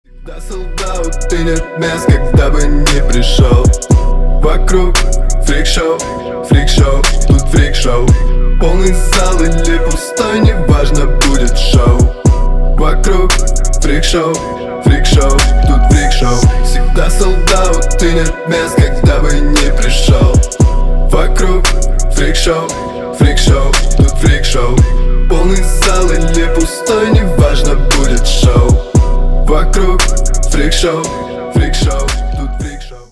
• Качество: 320, Stereo
спокойные
качающие